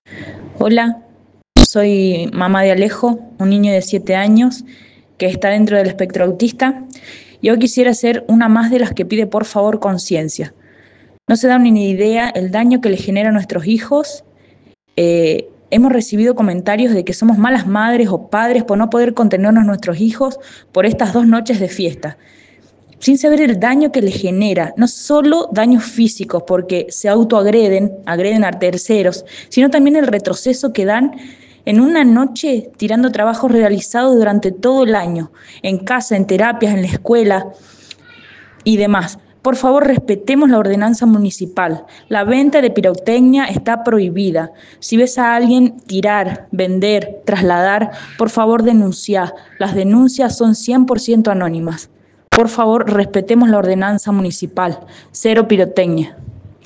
Escucha los audios de madres ceresinas de chicos con autismo….